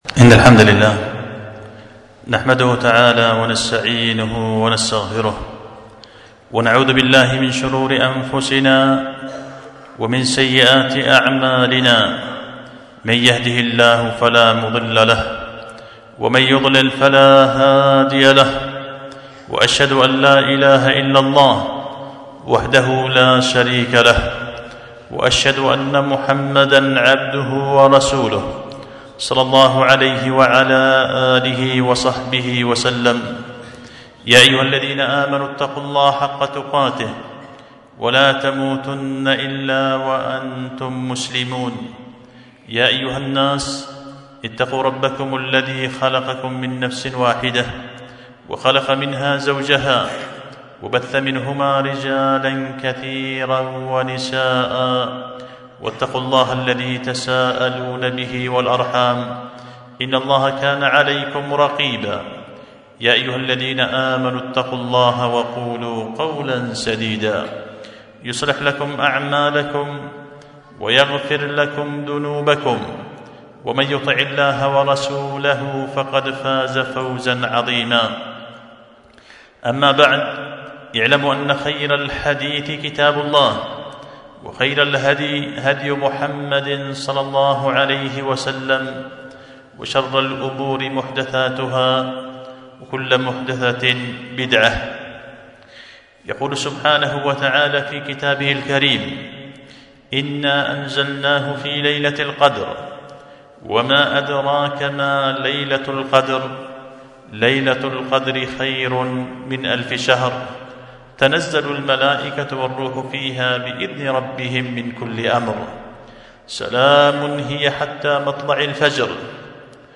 خطبة جمعة بعنوان اكتمال البدر في الحديث عن زكاة الفطر وليلة القدر